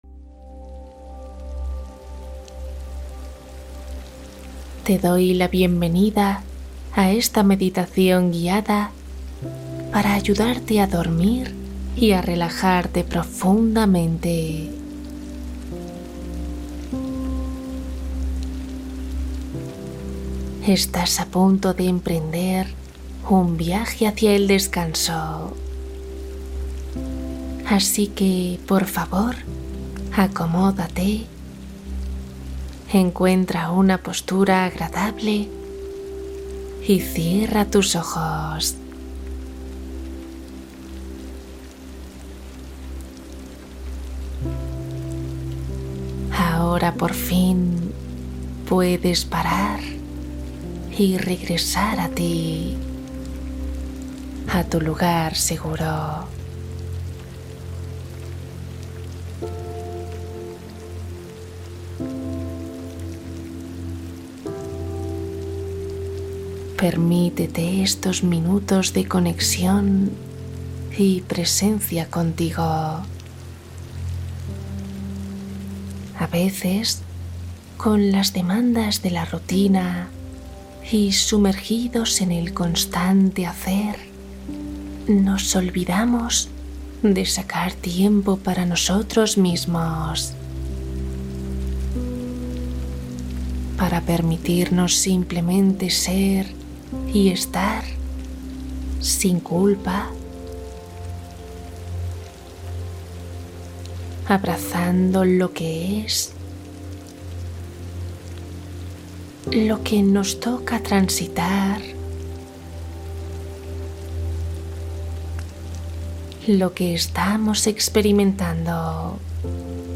Dormir con lluvia suave Meditación guiada para sueño profundo (1 hora)